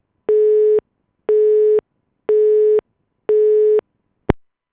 outgoingcallbusy.wav